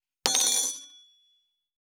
248,食器にスプーンを置く,ガラスがこすれあう擦れ合う音,コトン,トン,ゴト,ポン,ガシャン,ドスン,ストン,カチ,タン,バタン,スッ,サッ,コン,ペタ,パタ,チョン,コス,カラン,ドン,チャリン,効果音,環境音,BGM,
コップ効果音厨房/台所/レストラン/kitchen物を置く食器